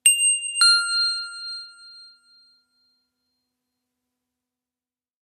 sound_notification.wav